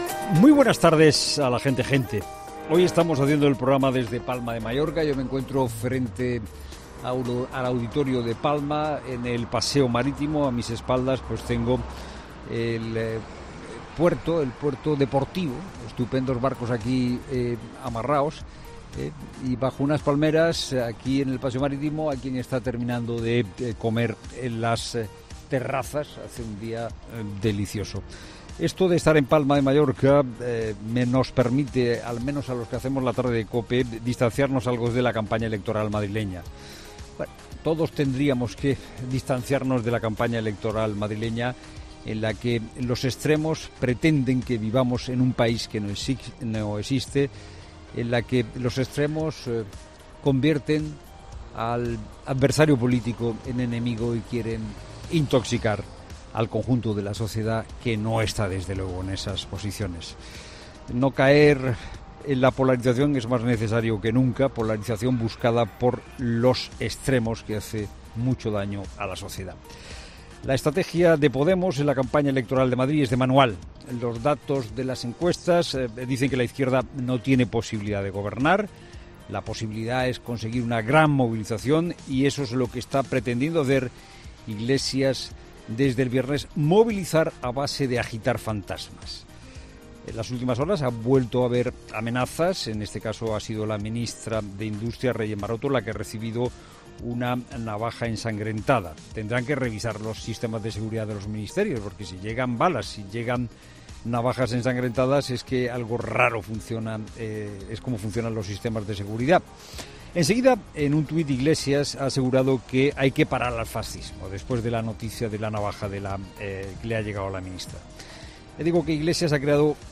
Estamos hoy haciendo el programa desde Palma de Mallorca, lo que nos permite, afortunamdamente, tomar algo de distancia, de la campaña electoral madrileña.